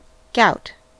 Ääntäminen
US : IPA : [ɡɑʊt]